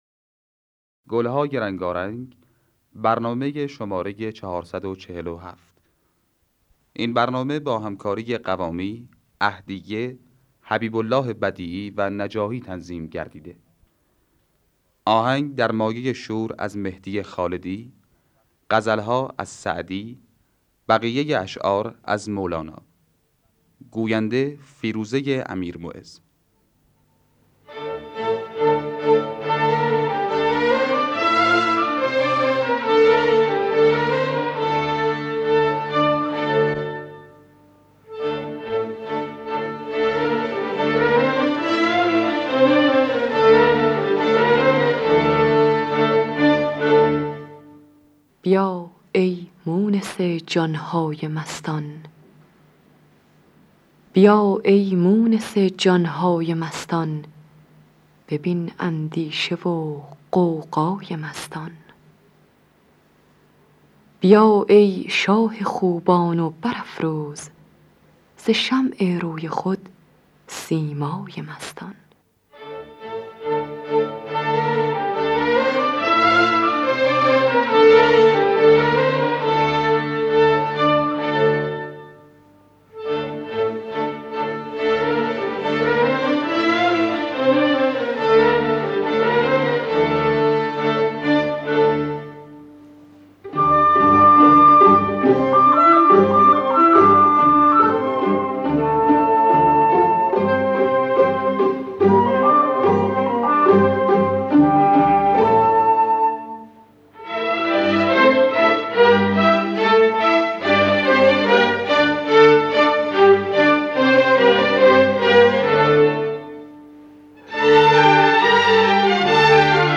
دانلود گلهای رنگارنگ ۴۴۷ با صدای عهدیه، حسین قوامی در دستگاه شور.